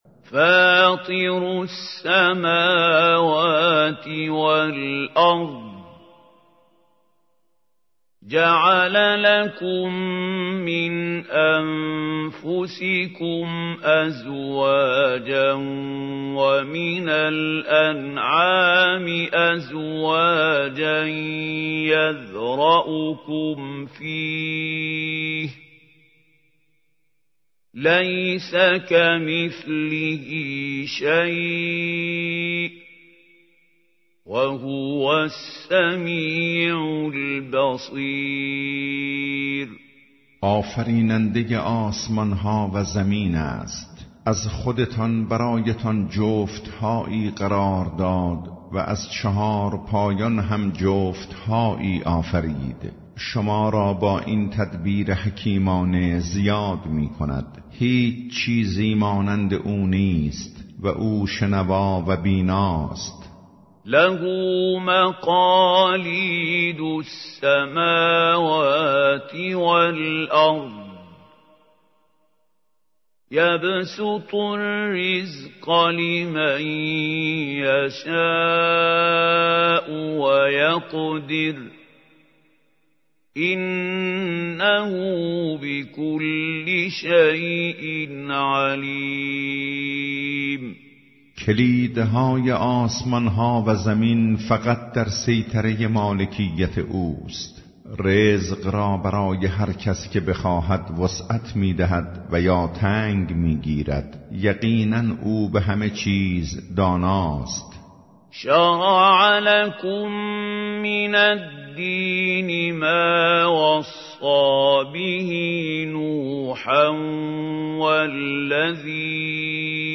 ترجمه گویای قرآن کریم - جزء ۲۵